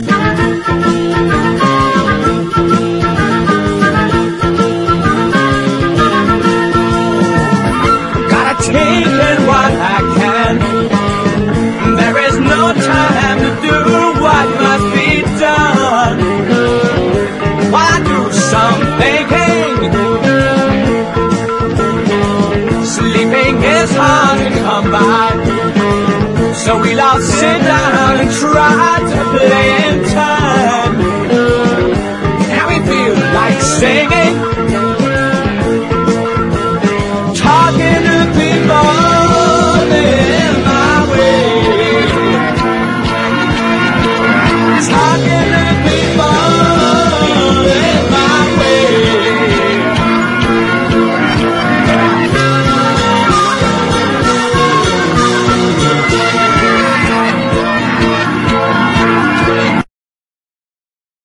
ROCK / 70'S / PSYCHEDELIC / S.S.W. / SWAMP / STEEL PAN
ソフト・サイケ～アシッド・フォーク～スワンプ～S.S.W.とヴァラエティ豊かなソロ作！